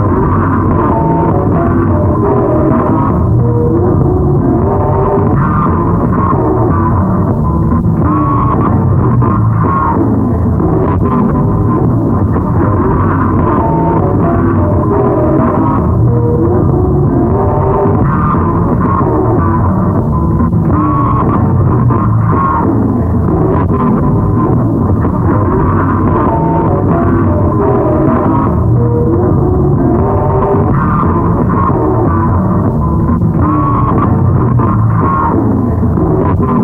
卷对卷磁带循环样本 " 慢下来的锄头
描述：慢下来的乡村音乐歌曲，声音被采样并分层在1/4"磁带上，被切割并物理循环
Tag: 切好的 音调下降 样品 带环 语音